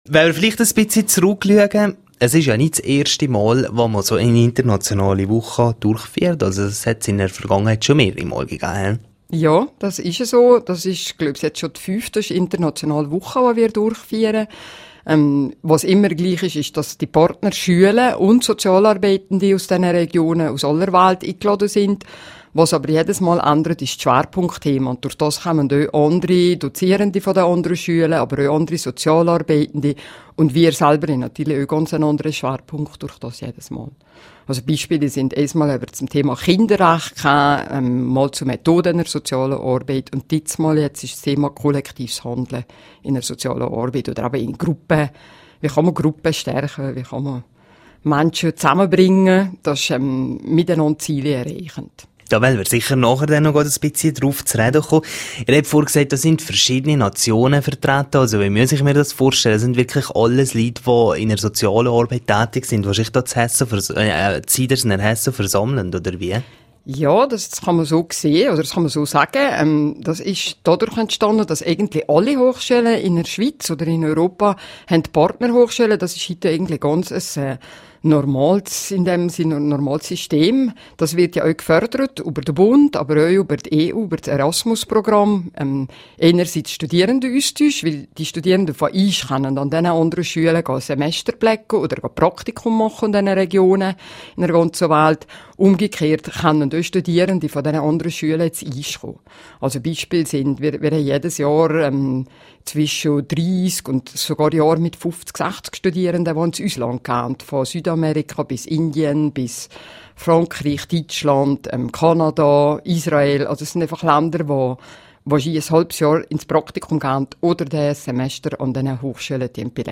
Ausführliches Interview